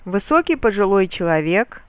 В обоих случаях мелодический рисунок носит нисходящий характер (См. мелодическую схему):
ударные слоги в синтагме произносятся на постепенно понижающемся тоне, а на ударном слоге последнего слова наблюдается резкое падение, характеризующее конечную синтагму (или же односинтагменную фразу).